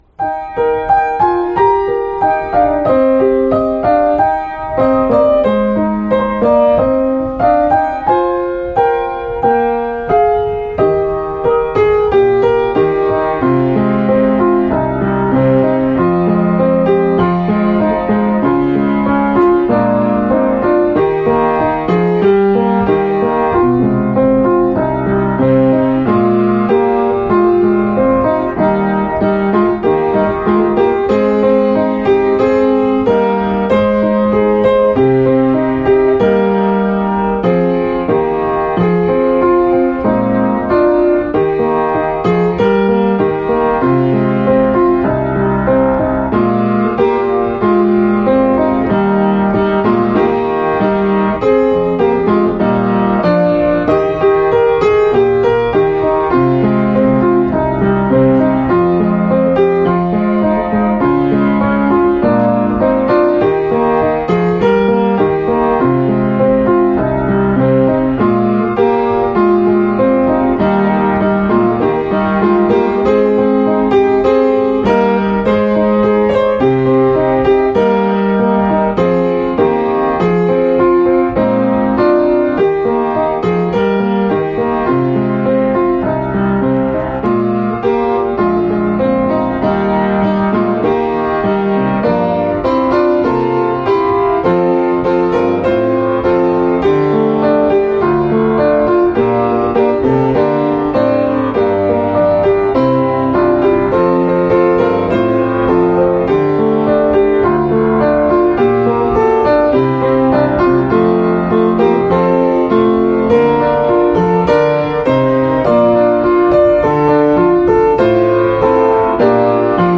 In The Bleak Midwinter – Accompaniment